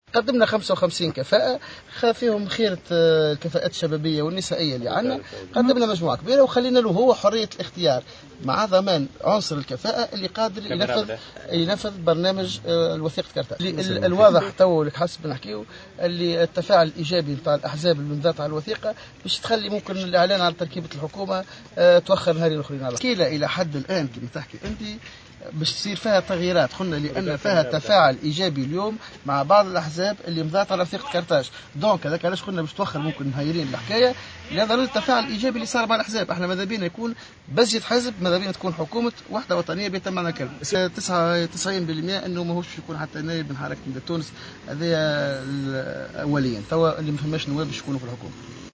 وأضاف طوبال في تصريح إعلامي أمس على هامش لقاء نواب من الكتلة مع رئيس الحكومة المكلف أن إعلان الحكومة سيتأخر ليومين آخرين باعتبار أن تشكيلة الحكومة ستشهد عدة تغييرات بعد تفاعل العديد مع الأحزاب الموقعة على وثيقة قرطاج.